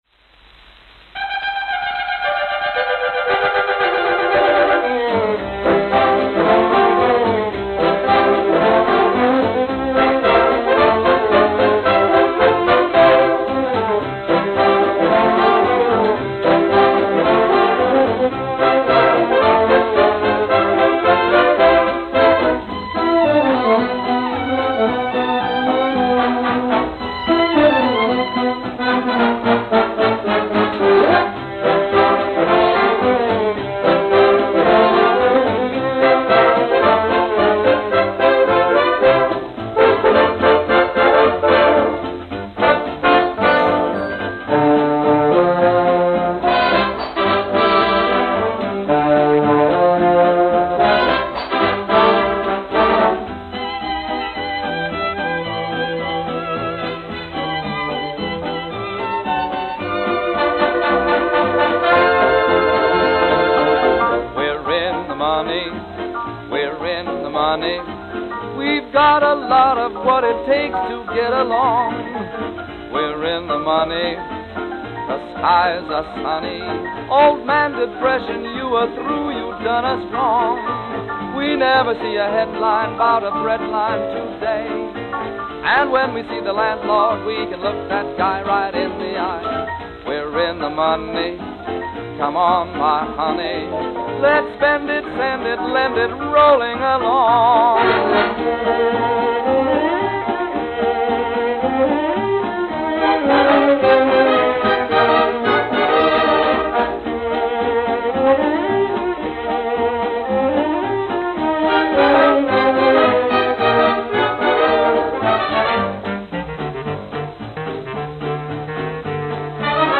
vocal
Note: Skipping on master or stamper.